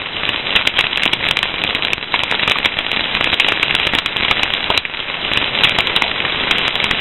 Crackling Fire